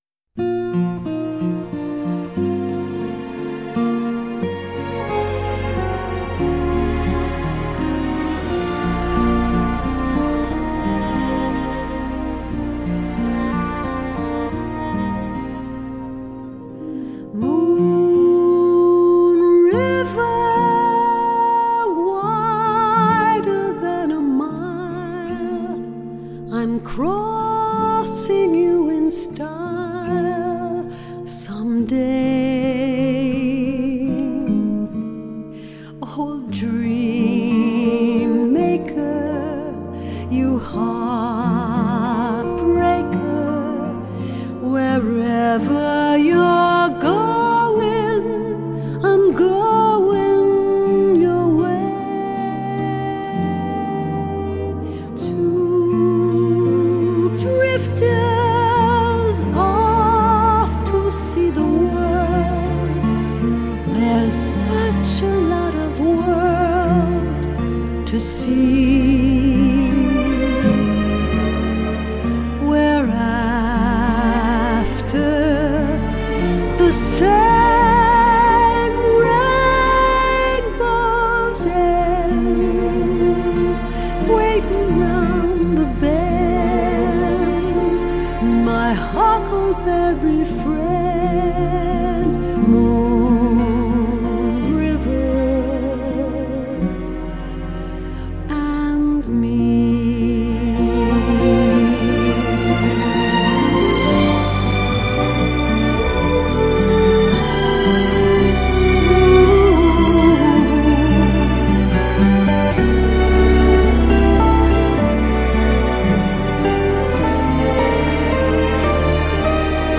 以一支七十五個人編制的大型管弦樂團搭配